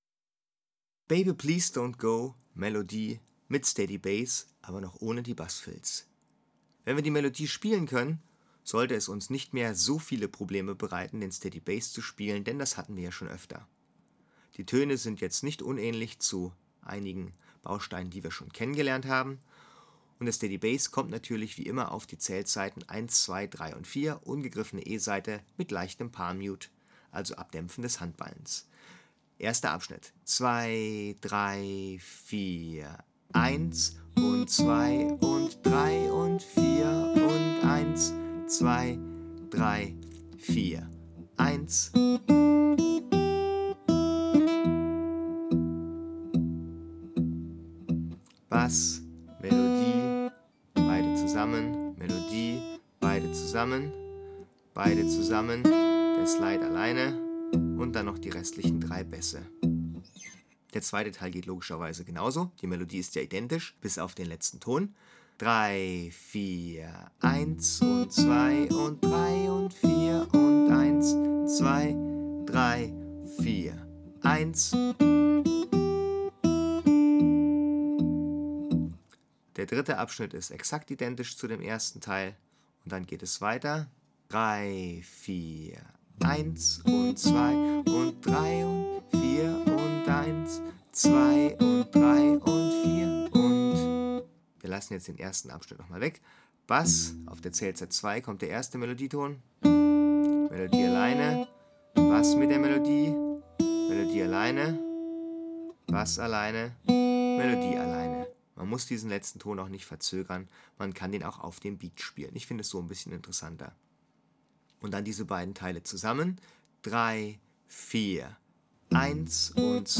Melodie mit Steady Bass